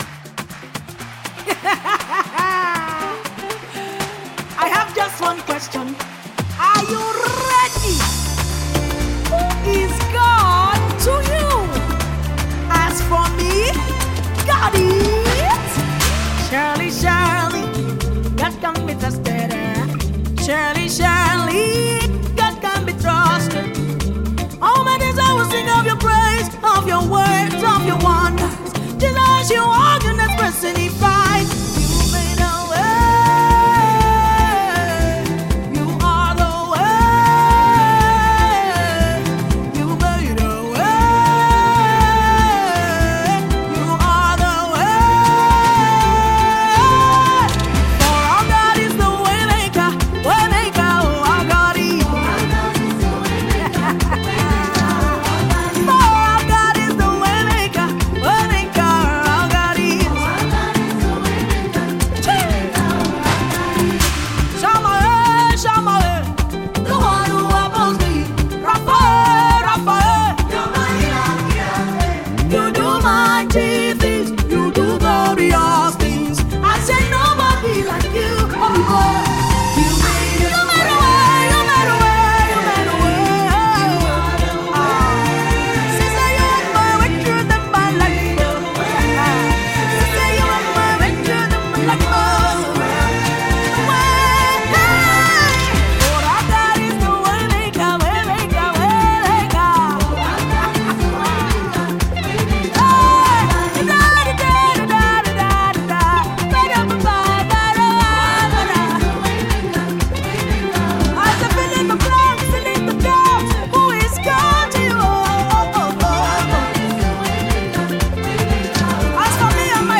GospelMusic
Nigerian Gospel songstress, singer and songwriter